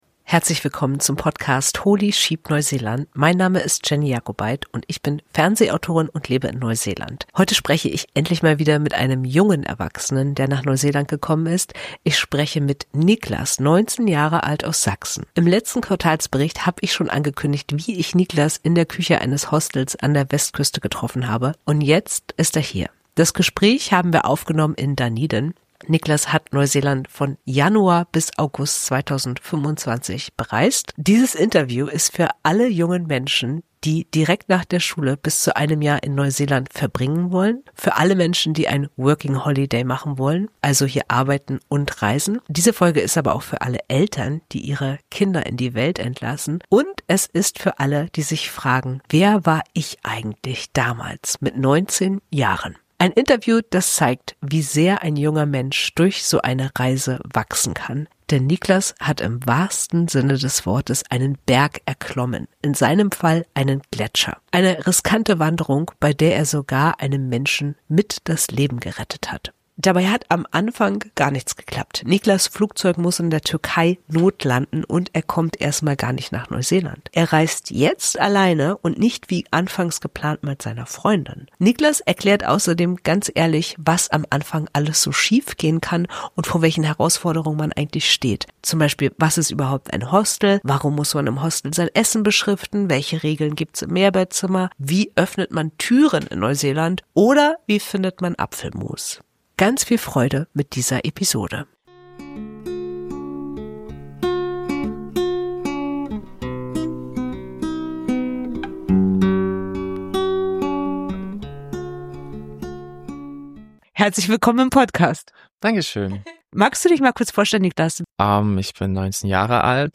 Ein Interview, das zeigt, wie sehr ein junger Mensch durch so eine Reise wachsen kann.